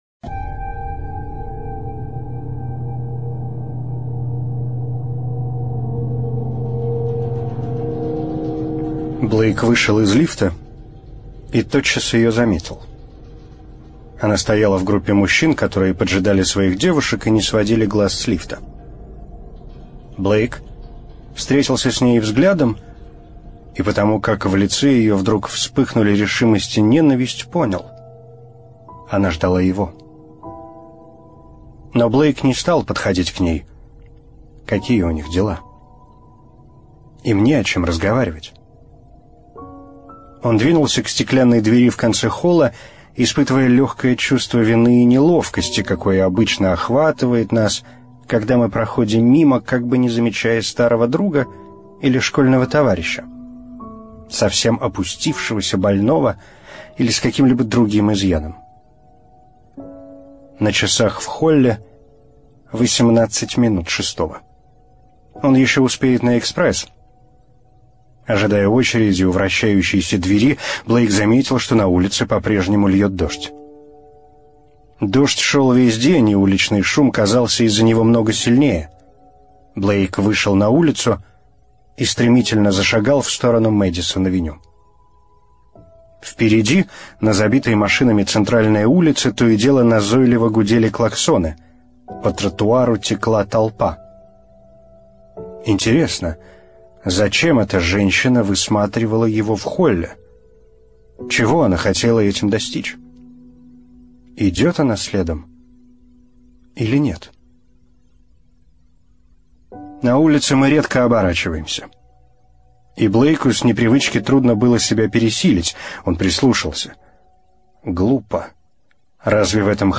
Студия звукозаписиРадио Маяк